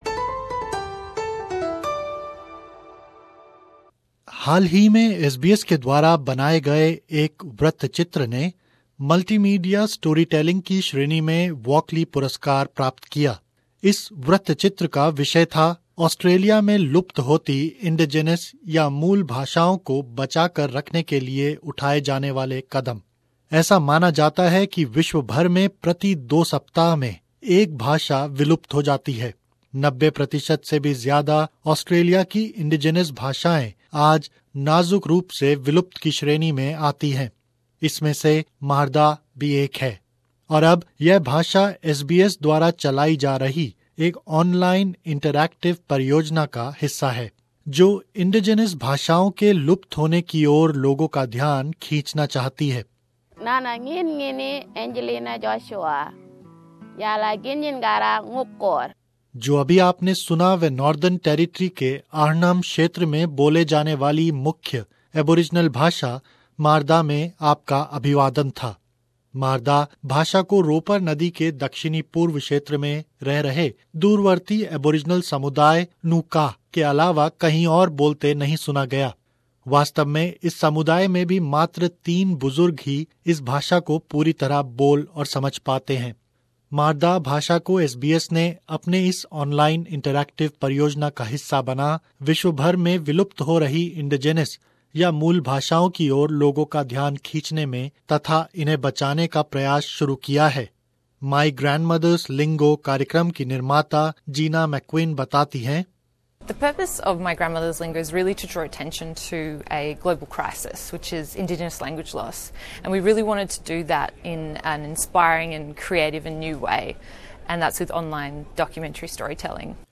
That was a greeting in the Marra language - one of the Aboriginal languages spoken in Arnhem Land in the Northern Territory.